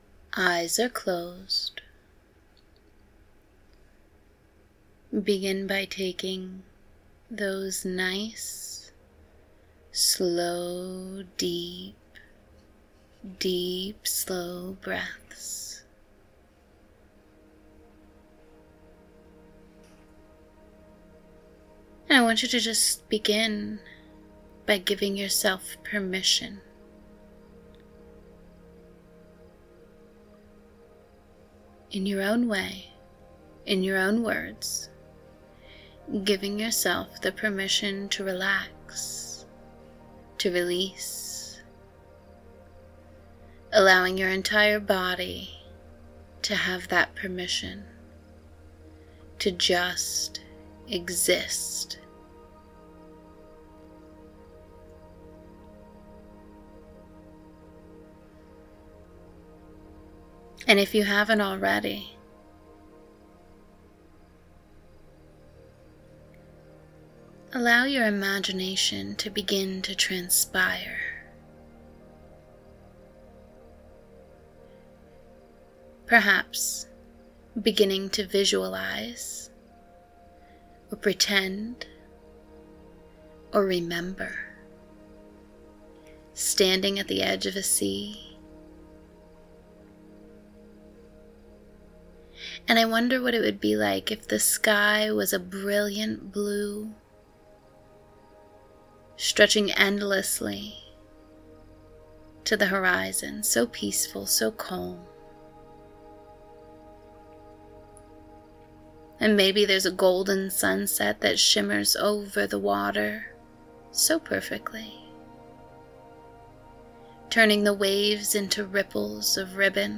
FREE: Sleep Hypnosis Recording press play when you're snuggled into bed and allow yourself to be guided into a deep, restful slumber!